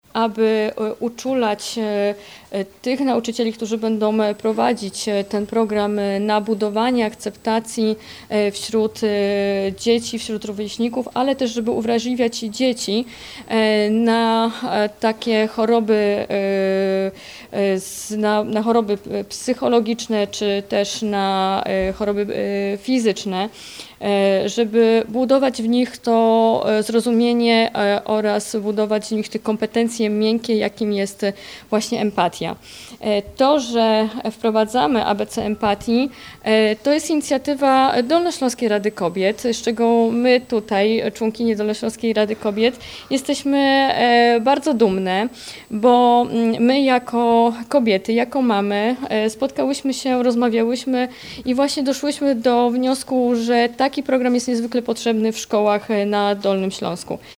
–  Jako samorząd województwa widzimy wyzwania, z którymi boryka się dzisiejsza współczesna szkoła – mówi Natalia Gołąb – Członkini Zarządu Województwa Dolnośląskiego.